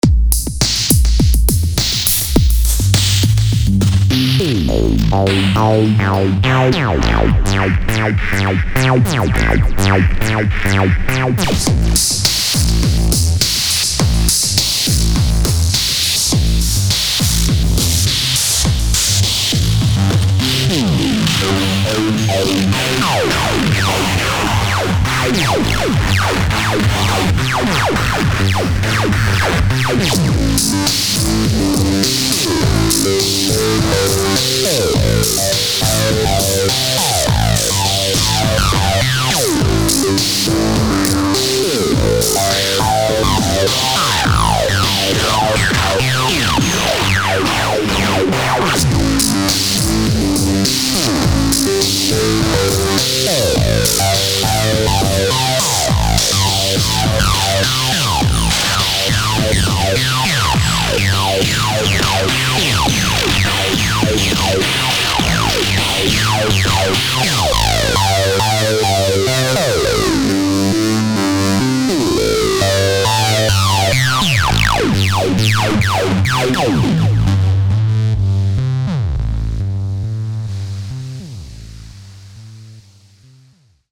Dirty example. OT (single cycle saw + MD samples) through 2 ADs. Bypass the first 5 bars.